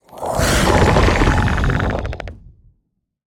sounds / mob / warden / roar_4.ogg
roar_4.ogg